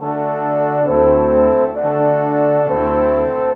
Rock-Pop 01 Brass 08.wav